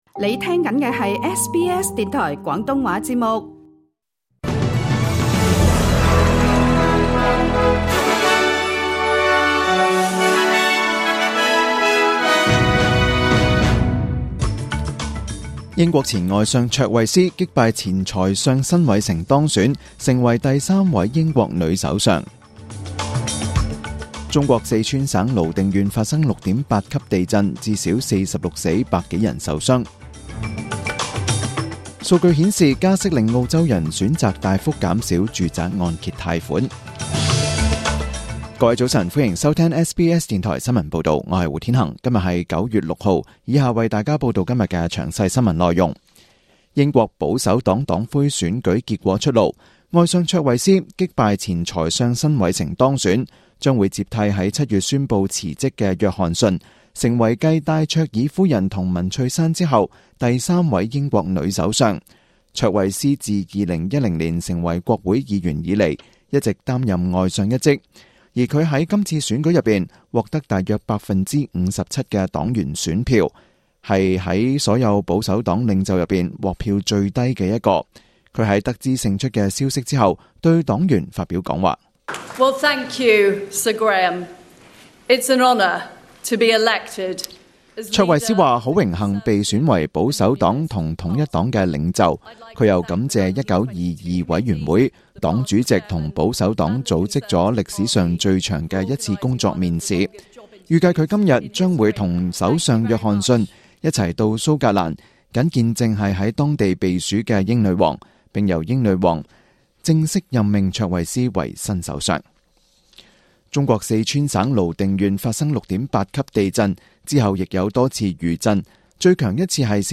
SBS廣東話節目中文新聞 Source: SBS / SBS News